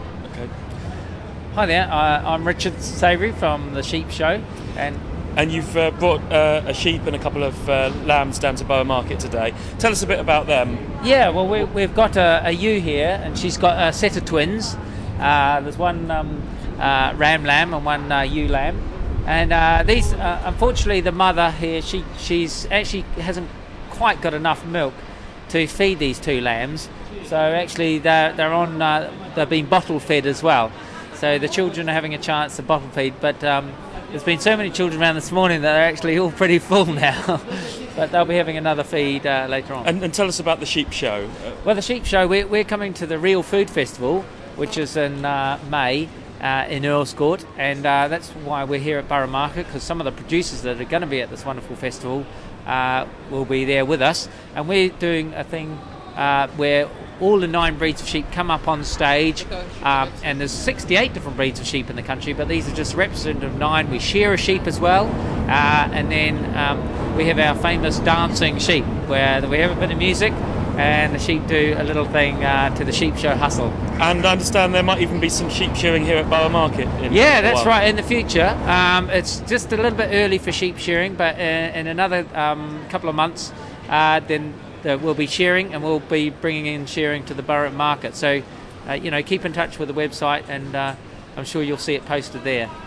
Adorable 3-week-old lambs at Borough Market with Real Food Festival